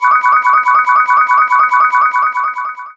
Added correct and incorrect buzzers during multiple choice rounds.
Round 1 - Correct.wav